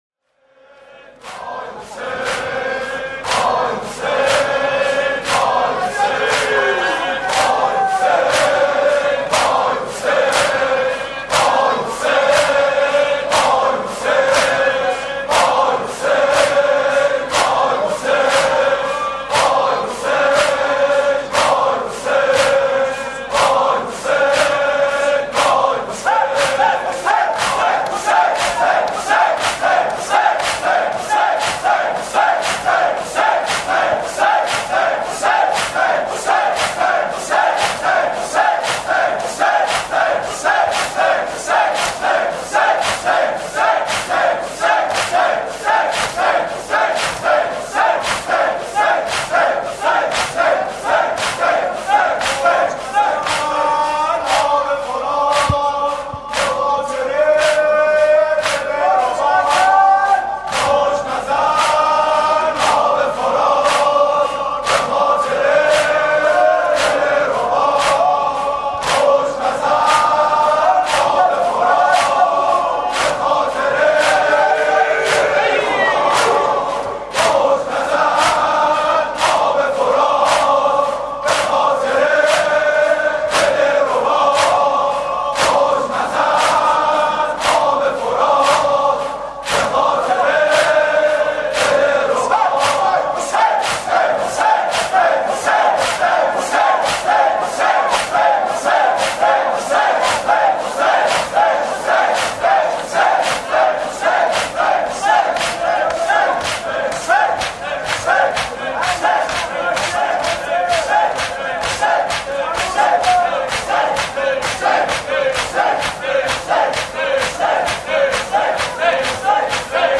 ذکر